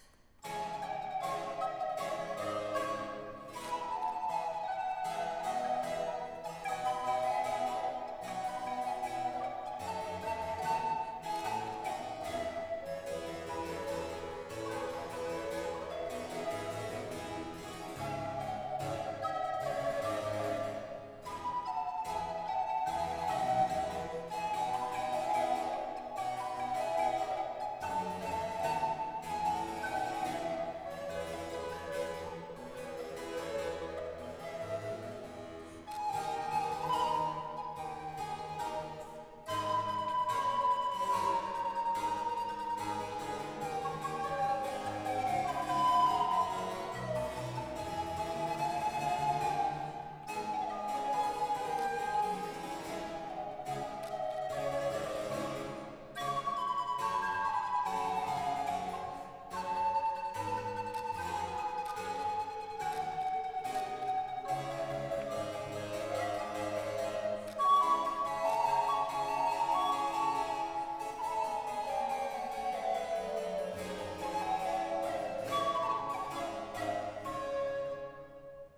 pour deux flûtes à bec et basse continue
Concert
flûte à bec
clavecin
Genre : Musique Baroque.
Extrait-La-Camerata-Chiara-Sammartini-Sonate-en-re-concert-Polyfolies-2020.wav